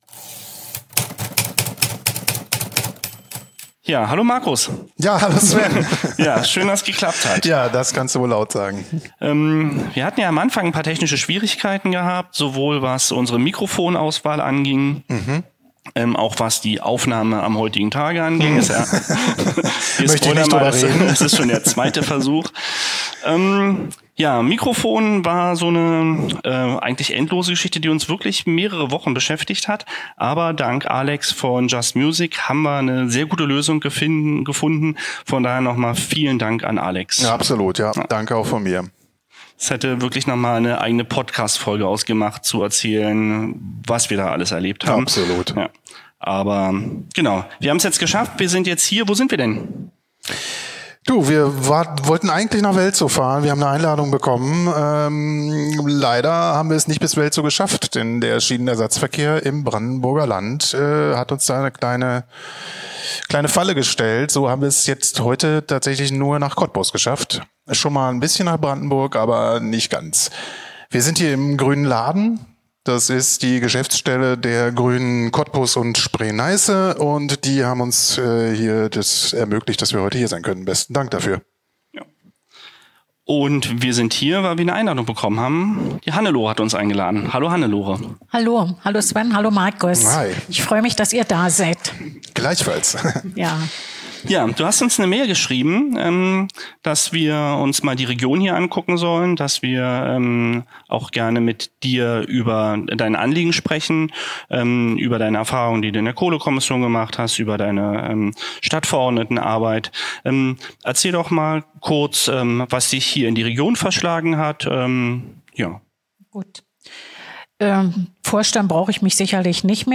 Wie diese Zukunft aussehen könnte, darüber sprechen wir in einem sehr kurzweiligen und persönlichen Gespräch mit ihr.